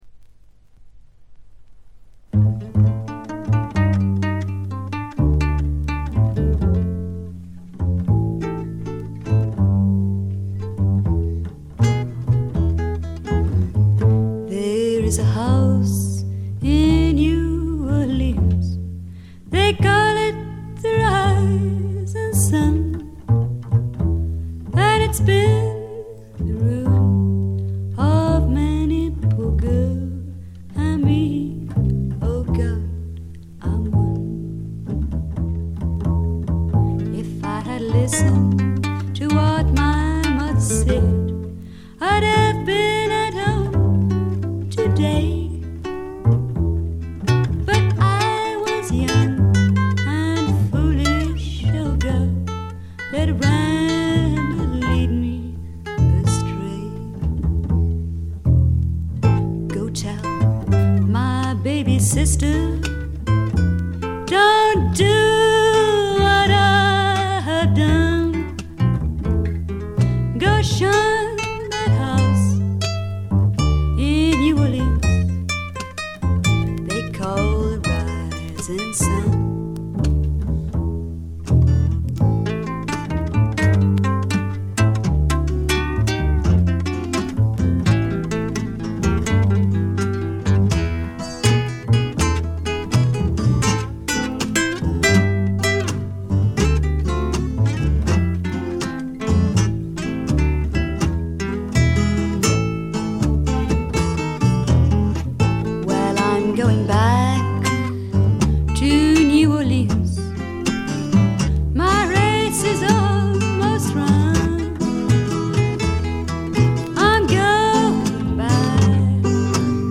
全体に軽微なバックグラウンドノイズ。
英国フィメール・フォークの大名作でもあります。
内容はというとほとんどがトラディショナル・ソングで、シンプルなアレンジに乗せた初々しい少女の息遣いがたまらない逸品です。
モノラル盤です。
試聴曲は現品からの取り込み音源です。